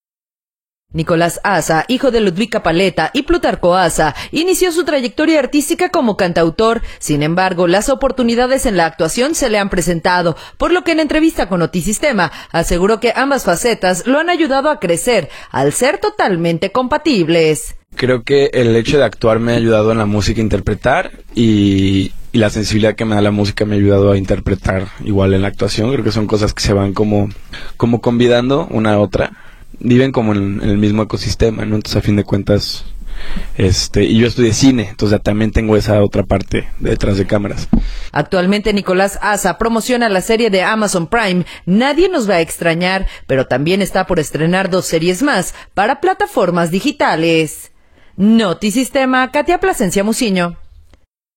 entrevista con Notisistema